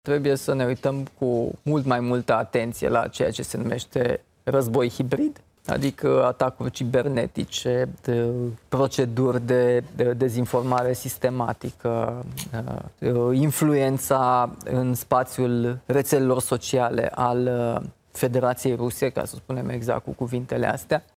„Sunt două schimbări majore: corupția și războiul hibrid”, a explicat Nicușor Dan, într-un interviu pentru Știrile ProTv